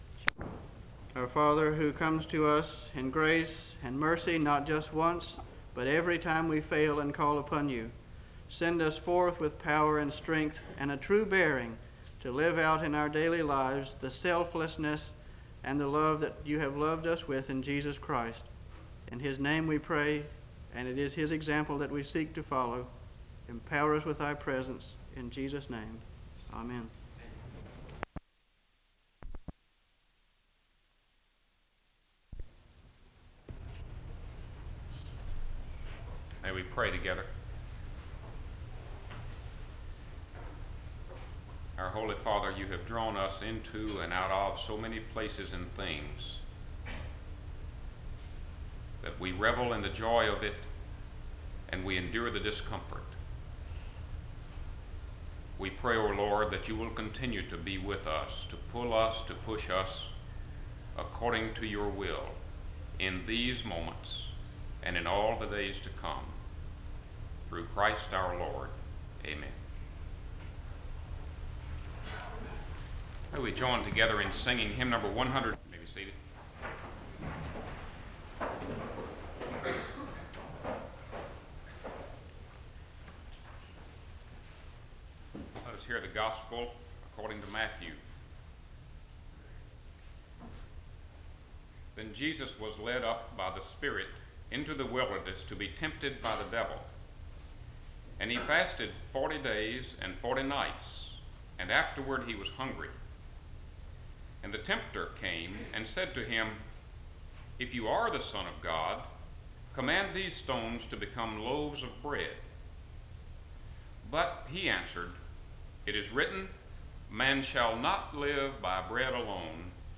The service begins with two prayers (00:00-01:09).
SEBTS Chapel and Special Event Recordings